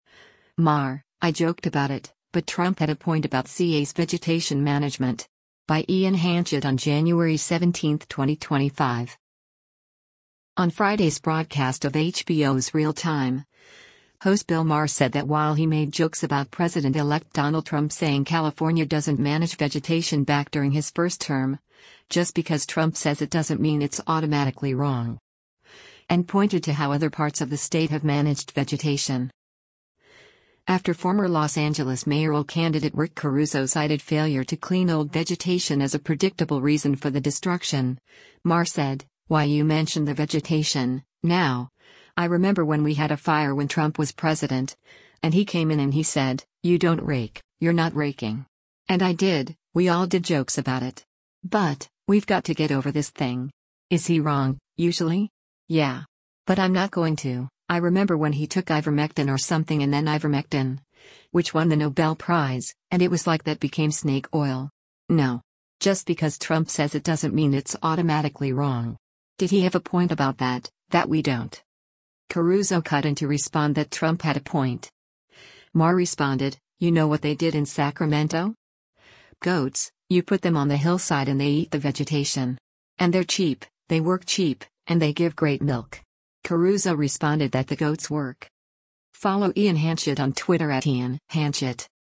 On Friday’s broadcast of HBO’s “Real Time,” host Bill Maher said that while he made jokes about President-Elect Donald Trump saying California doesn’t manage vegetation back during his first term, “Just because Trump says it doesn’t mean it’s automatically wrong.”